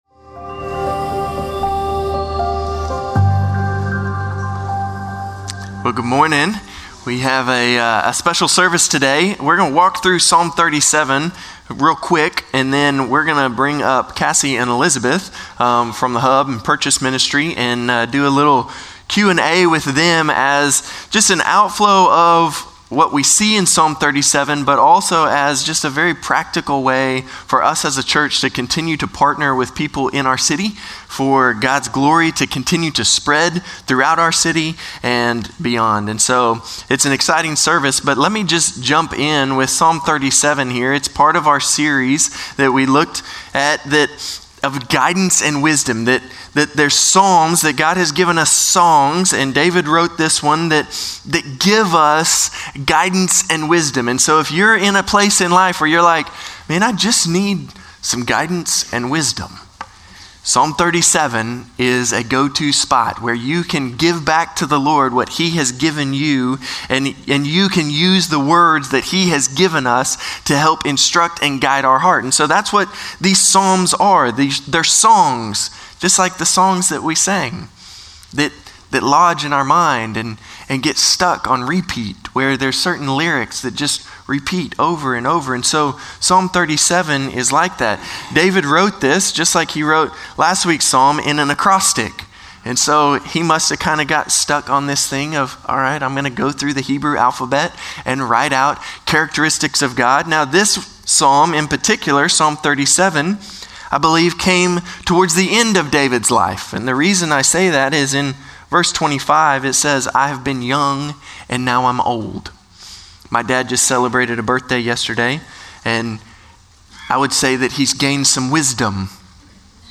Norris Ferry Sermons Feb. 2, 2025 -- The Book of Psalms - Psalm 37 Feb 02 2025 | 00:39:24 1x 00:00 / 00:39:24 Subscribe Share Spotify RSS Feed Share Link Embed